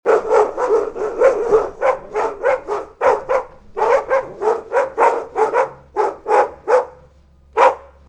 Three Dogs Barking Efecto de Sonido Descargar
Three Dogs Barking Botón de Sonido